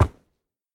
sounds / mob / horse / wood5.ogg